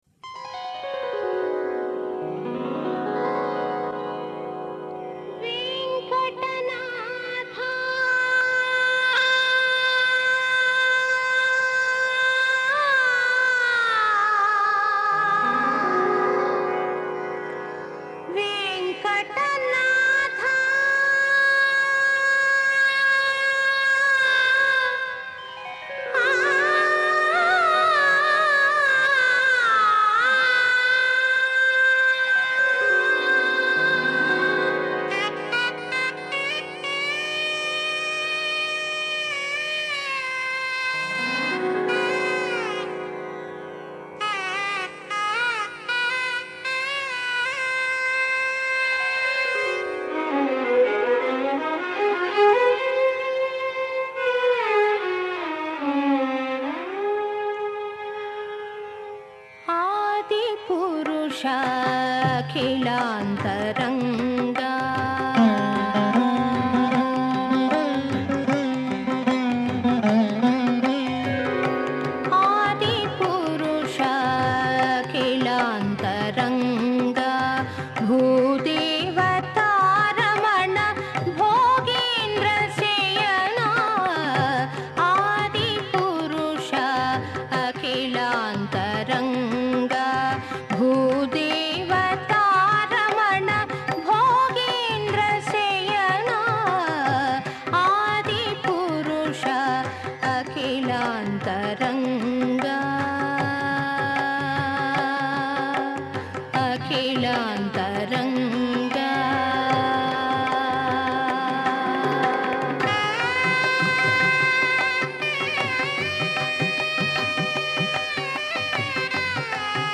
సంగీతం
సంకీర్తన